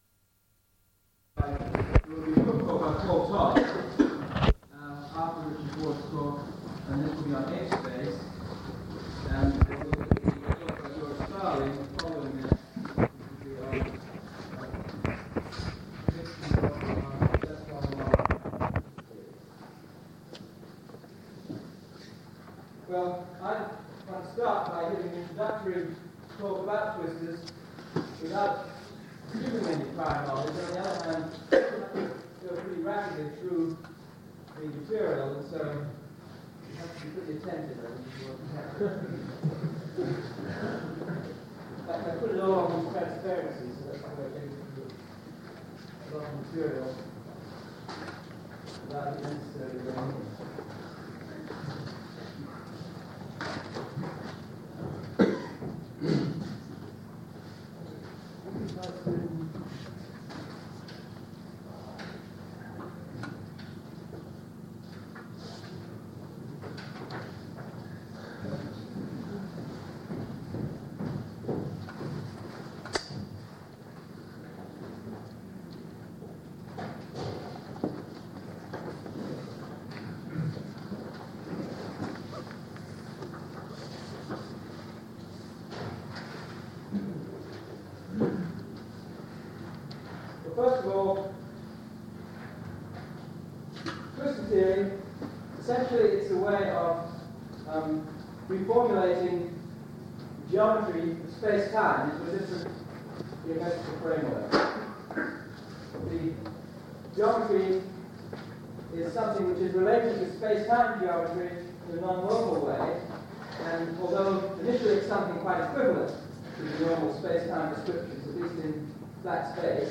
20/03/78 Oxford Twistor Seminar: Roger Penrose: General introduction to Twistor theory - Archive Trust for Research in Mathematical Sciences & Philosophy